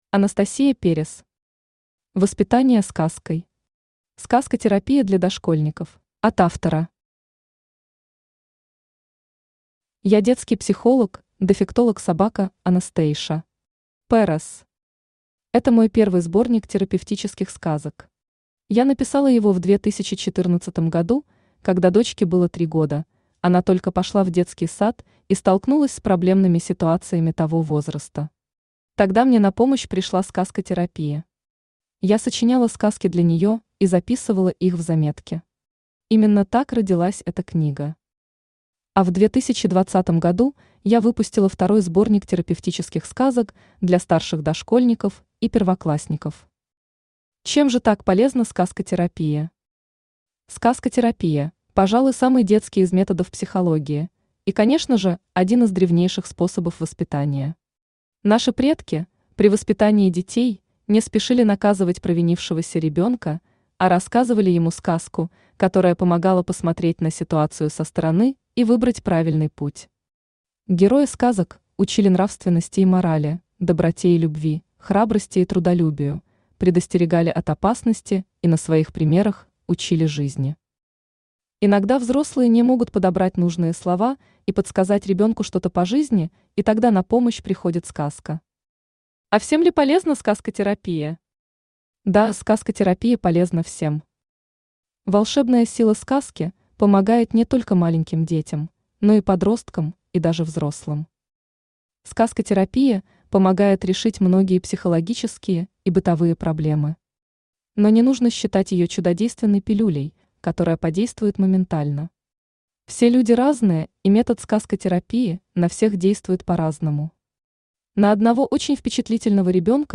Аудиокнига Воспитание сказкой. Сказкотерапия для дошкольников | Библиотека аудиокниг
Читает аудиокнигу Авточтец ЛитРес.